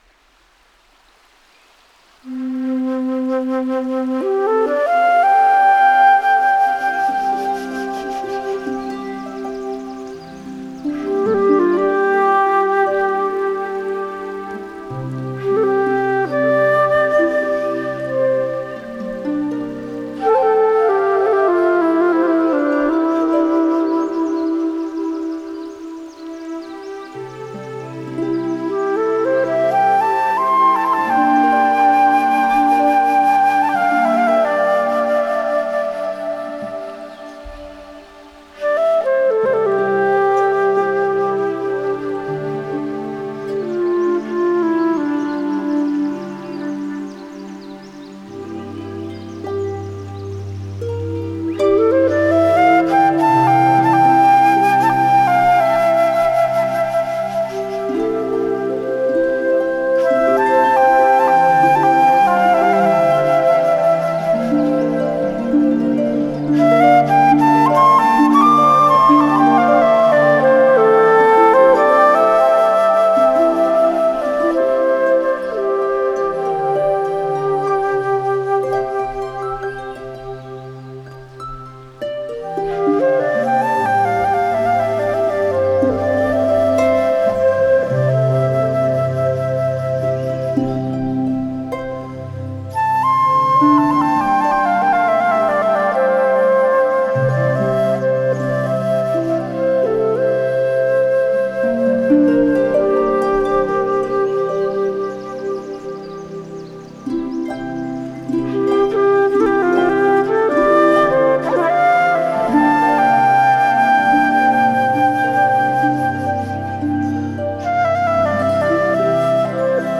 Нью эйдж Медитативная музыка New age